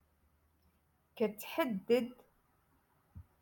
Moroccan Dialect- Rotation Six - Lesson TwoEleven